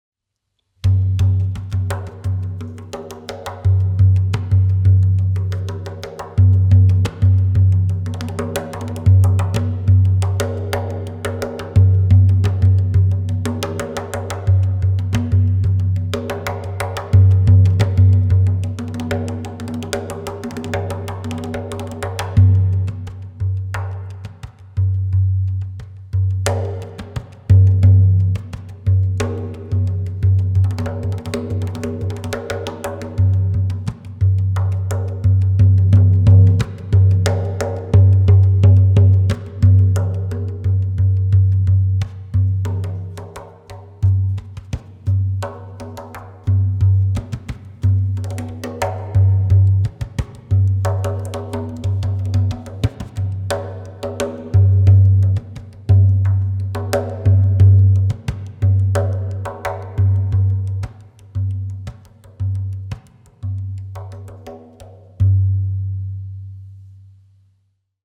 The Meinl Sonic Energy Hand Drums come equipped with a hand-selected goat skin head for uniform thickness and warm resonant tones.